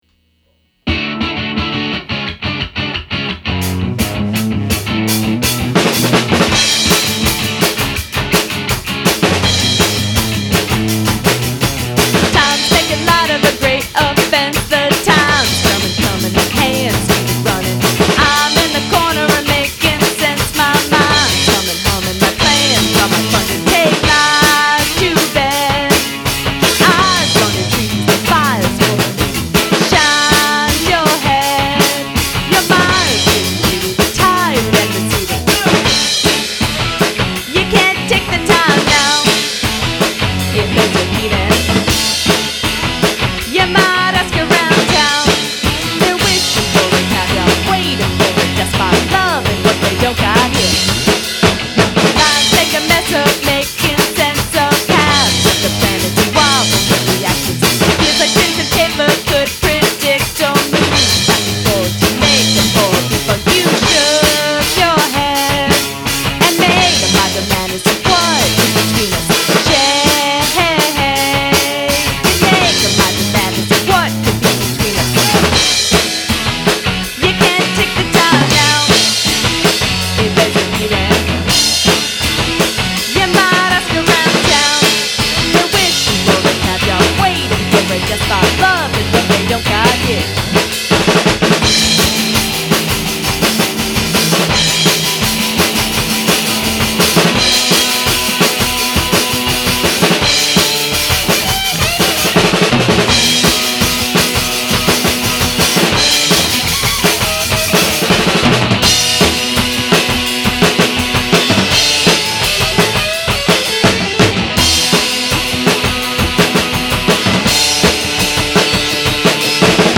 3-2-2010 Recording Session